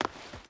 Stone Run 3.ogg